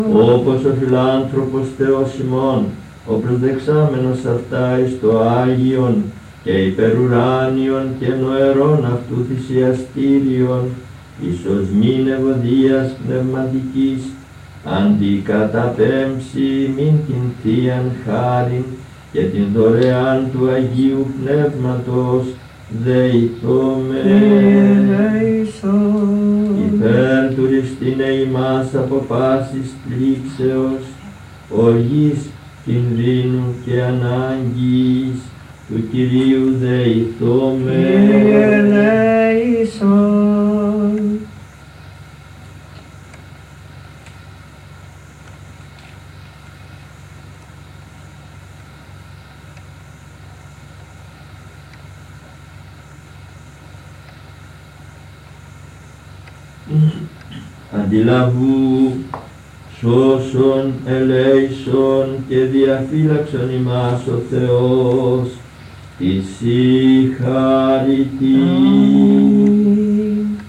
αὐτὰ τὰ μεγάλα κενὰ ἤχου (πρὸ τοῦ «Τὸν ἐπινίκιον ὕμνον», πρὸ τοῦ «Τὰ Σὰ ἐκ τῶν Σῶν, πρὸ τοῦ «Χάριτι καὶ οἰκτιρμοῖς» κτλ.) ποὺ ἀκούγονται ἀπὸ τὸν Ἅγιο Πορφύριο στὴν Θεία Λειτουργία (1977) ποὺ ἐτέλεσε σὲ ἕνα 10x5 ἐκκλησάκι (καὶ ἡ ὁποῖα ἐντελῶς τυχαῖα ἤρθε στὴν ἐπιφάνεια).